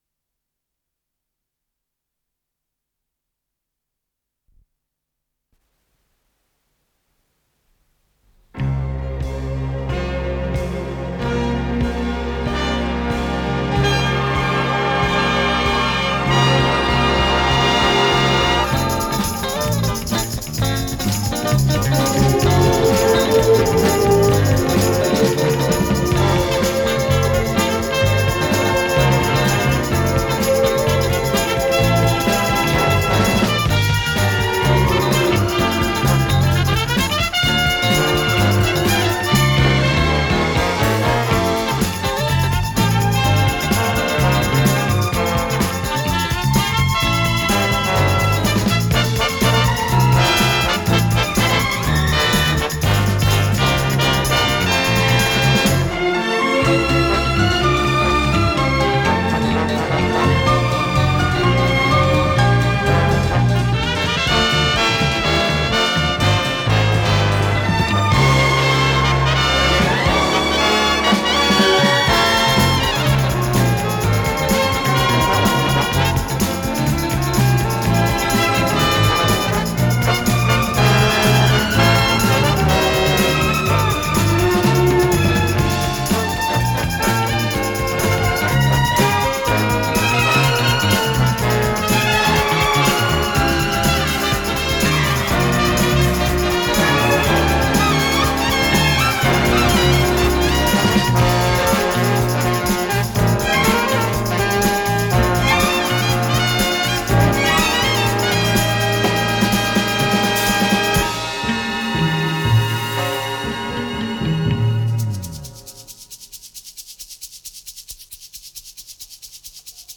фа мажор